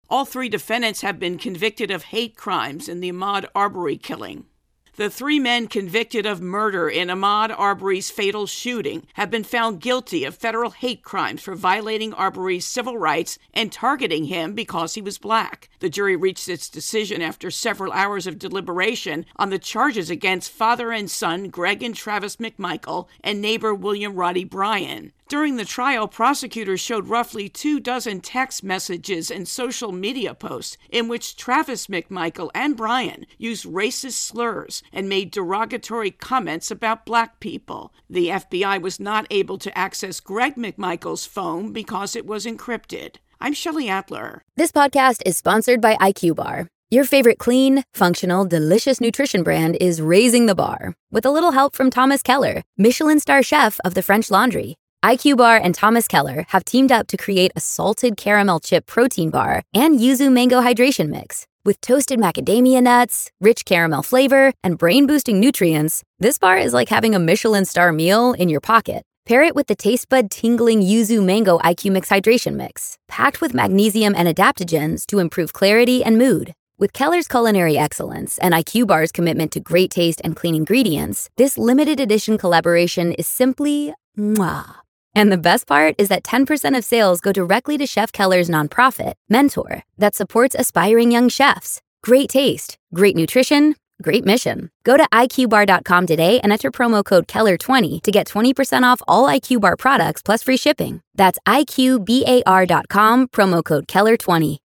Verdict intro and voicer